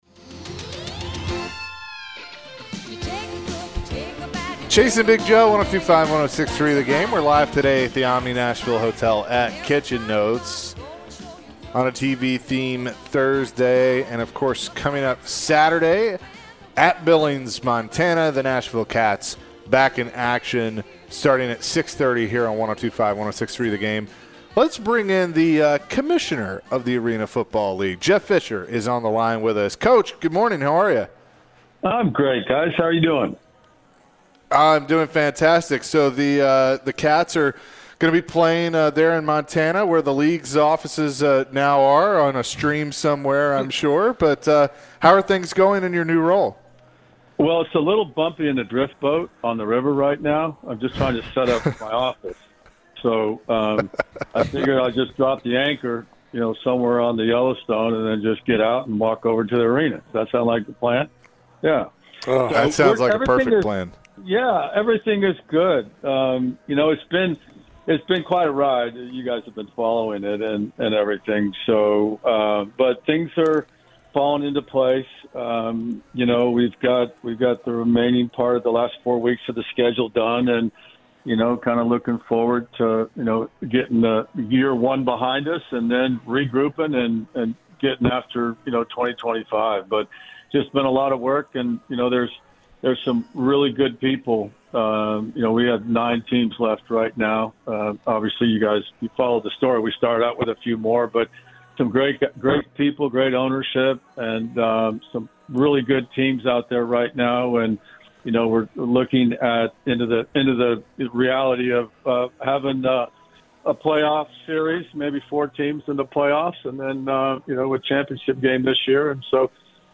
the interim general manager of the AFL Jeff Fisher, who joined the show. Jeff mentioned his new role with the league and an update about the Nashville Kats.